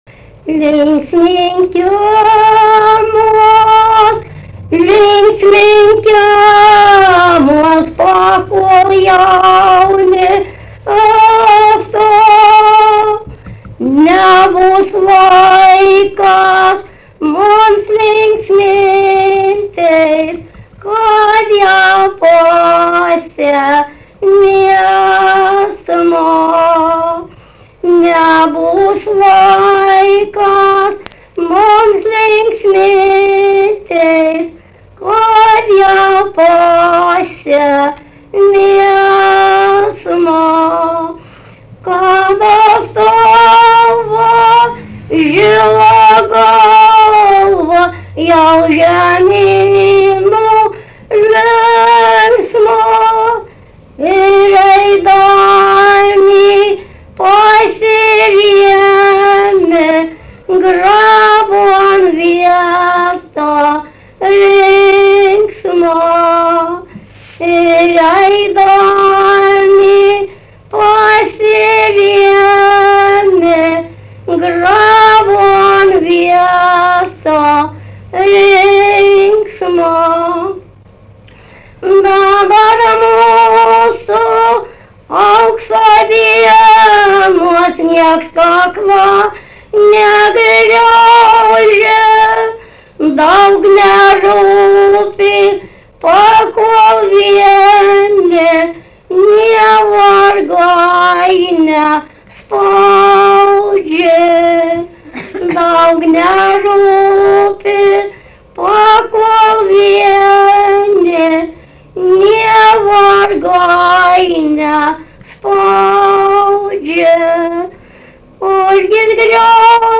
daina
Daukšiai (Skuodas)
vokalinis